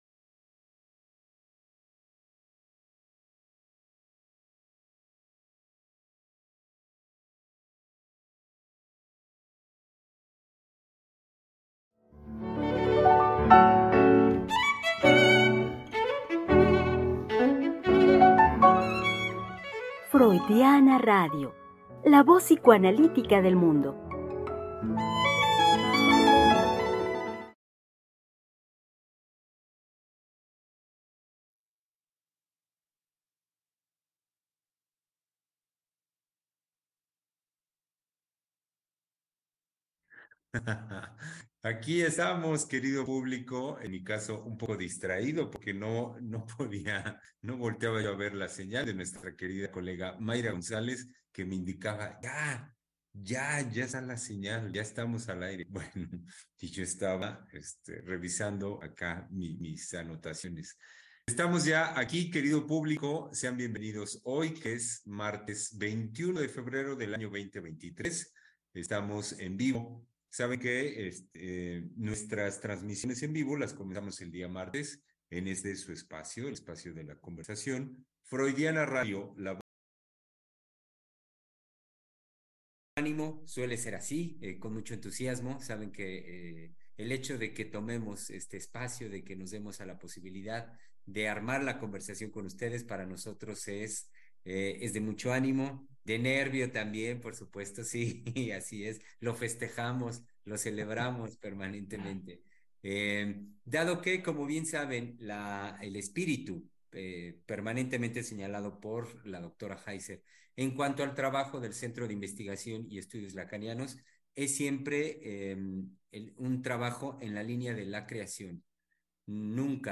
Es martes de Palabra de Hombre por freudiana radio y conversaremos con los psicoanalistas